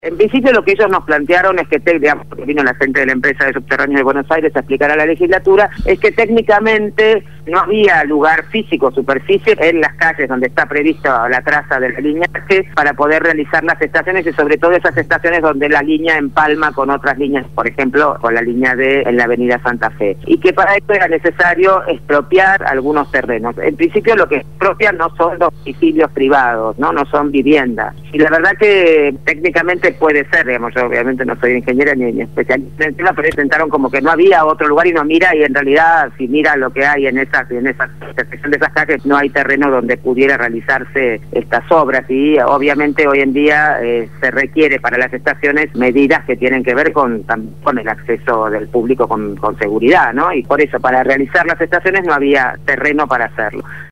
Gabriela Alegre, Legisladora porteña del Frente Para la Victoria (FPV) quien continuará su mandato a partir del próximo 10 de diciembre al haber sido electa el 10 de julio pasado,  habló esta mañana en el programa Punto de Partida de Radio Gráfica FM 89.3